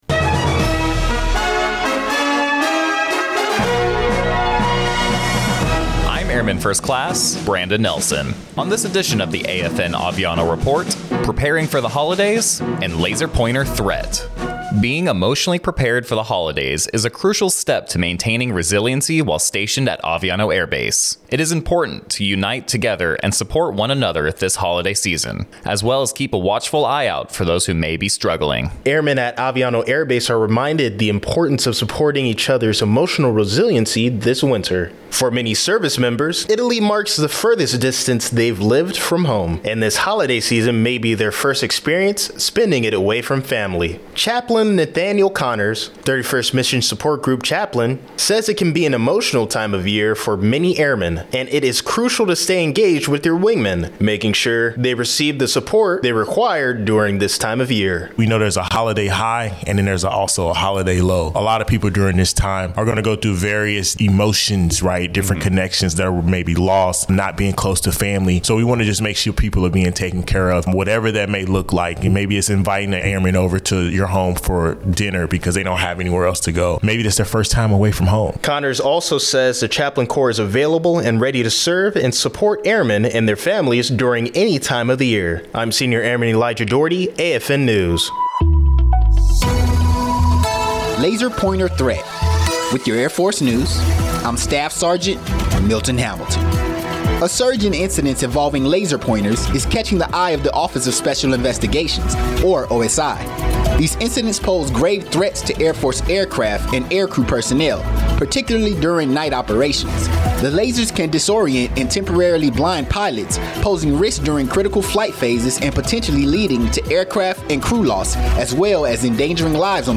American Forces Network (AFN) Aviano radio news reports on the importance of staying connected to each other as we approach the holiday season at Aviano Air Base.